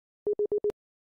9. Вызов или звонок по AirPods
airpods-zvonok-1.mp3